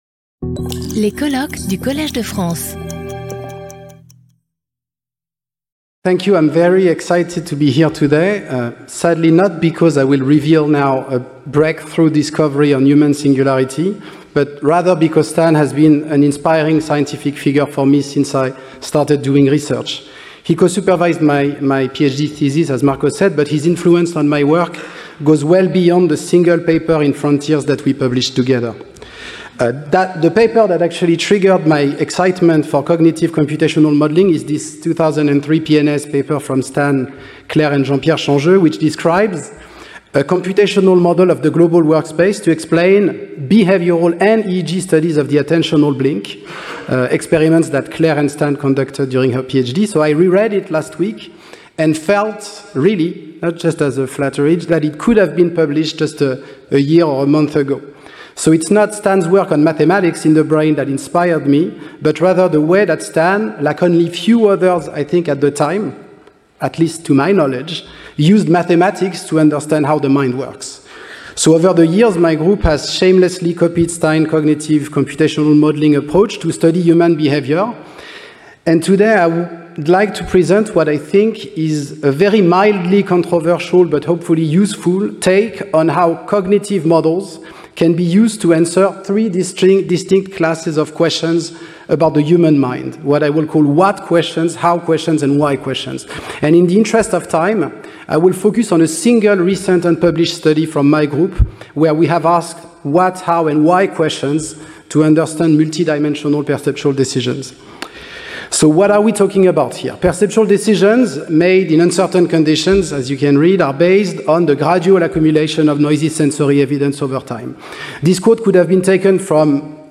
Lecture audio